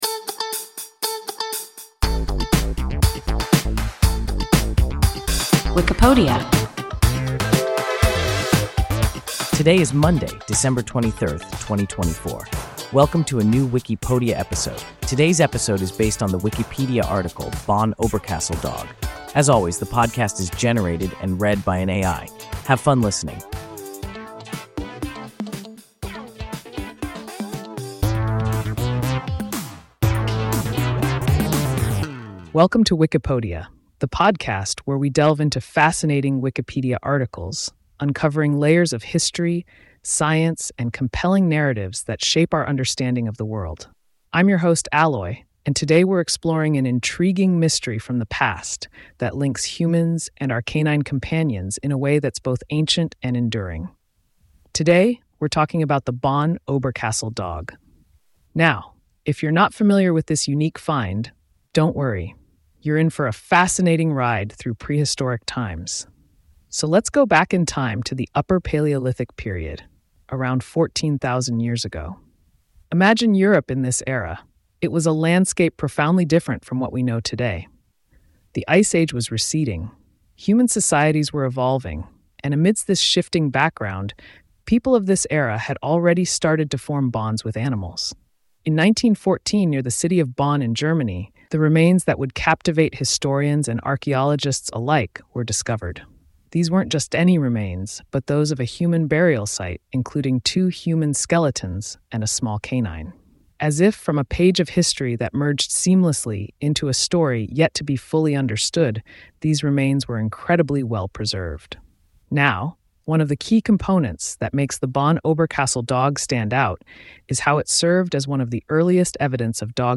Bonn–Oberkassel dog – WIKIPODIA – ein KI Podcast